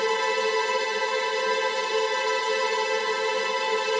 GS_TremString-Amin9.wav